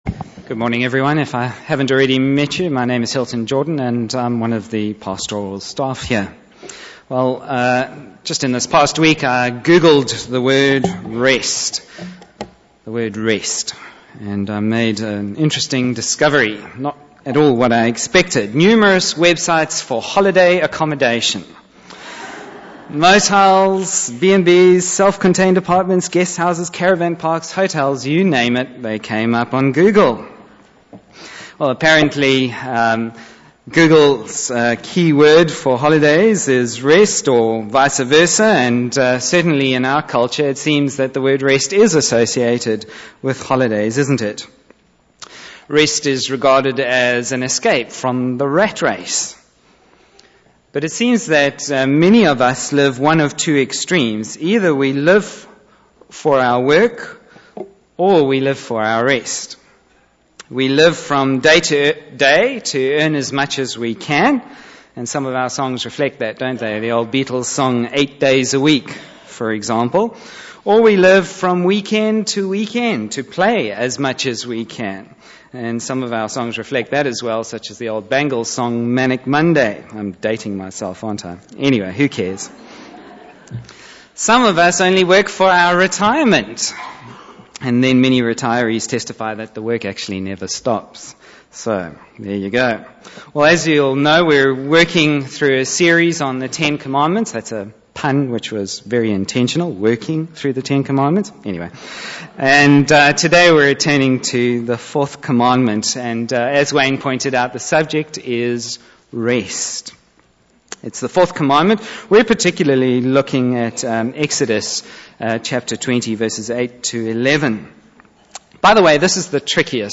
Bible Text: Matthew 11:25-30 | Preacher